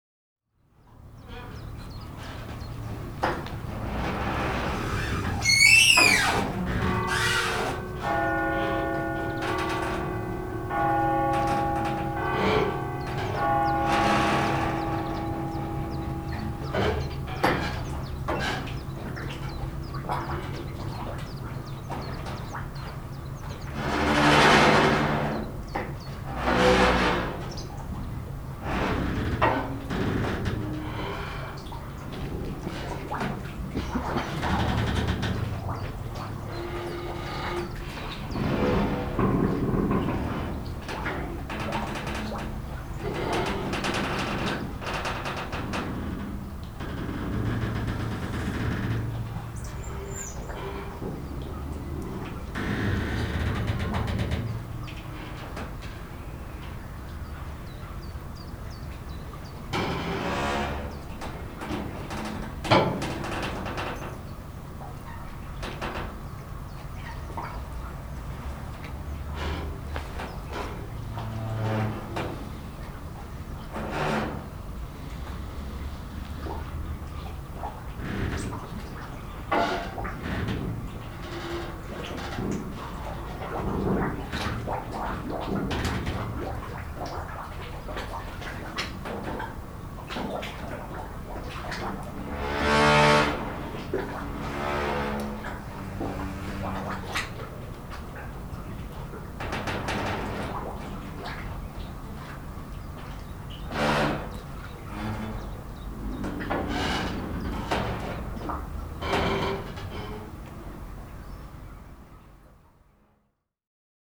Am Quai – Gänsehaut am Bodensee.
quai-romanshorn.mp3